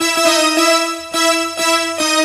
Synth Lick 50-01.wav